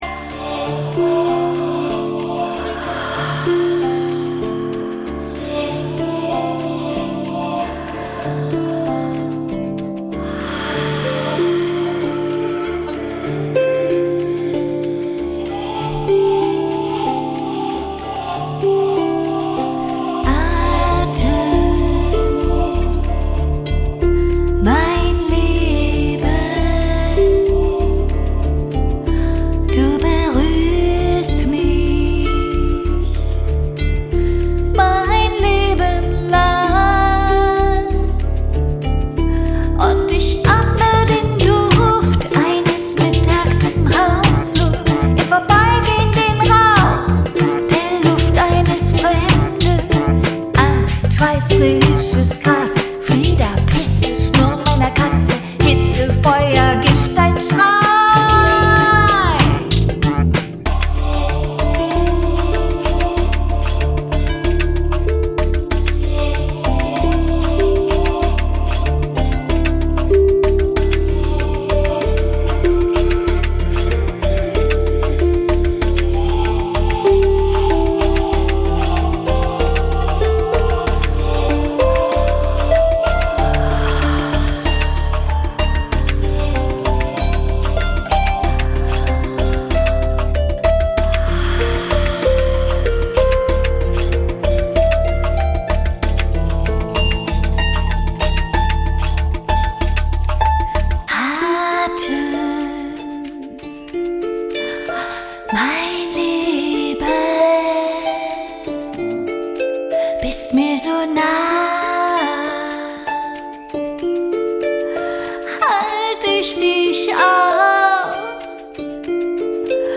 Klanggeschenk: Atemraum mit Gesang
Schließ die Augen und gönn dir Stille – Einladung zum bewussten Atem (≈ 4 Min).